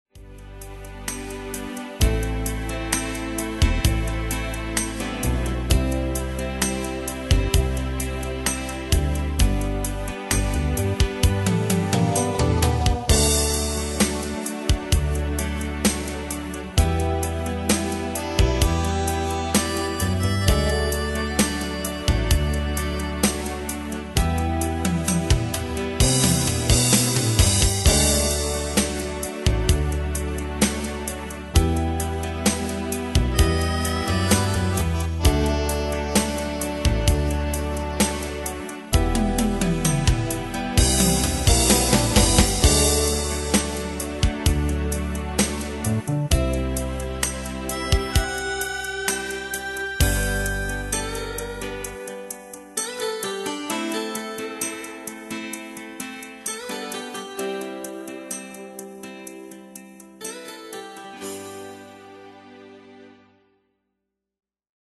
Bandes et Trames Sonores Professionnelles
Pro Backing Tracks